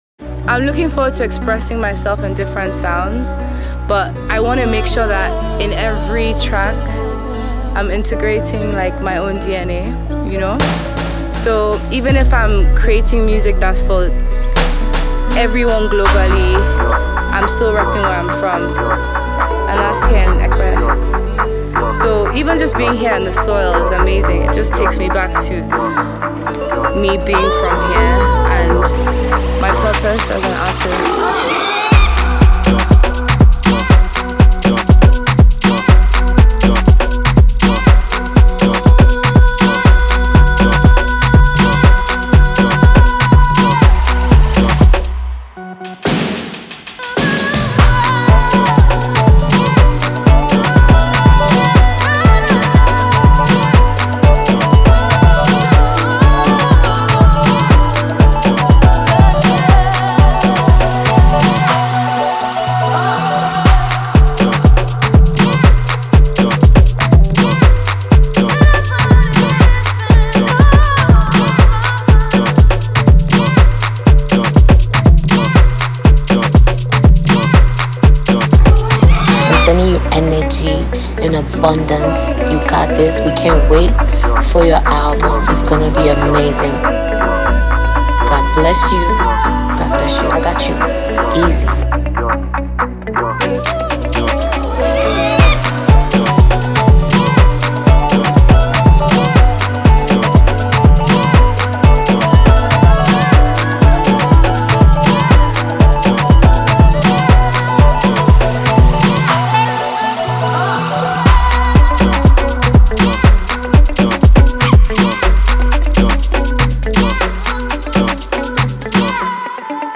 Take a listen to this free mp3 download Naija Afrobeat.